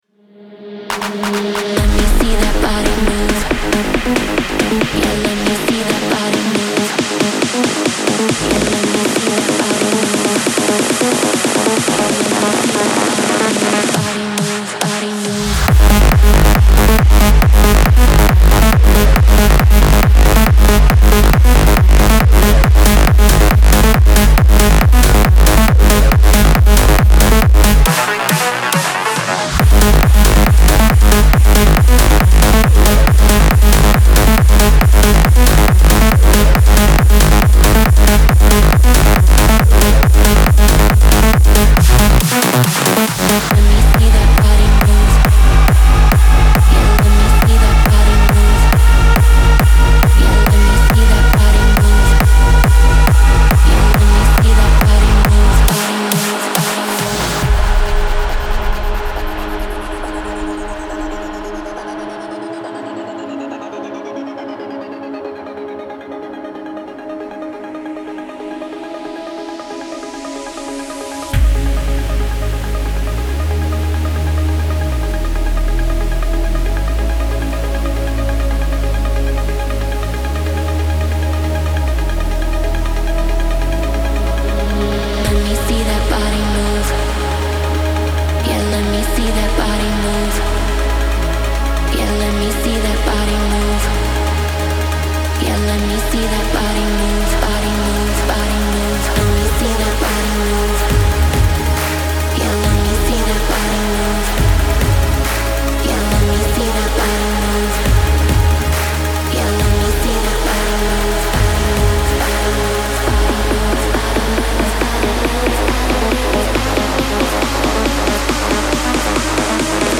• Жанр: Hardstyle, Dance